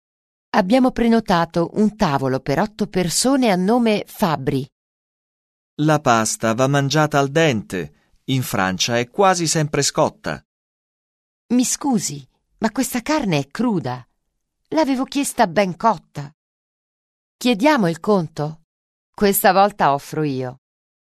Un peu de conversation - Au restaurant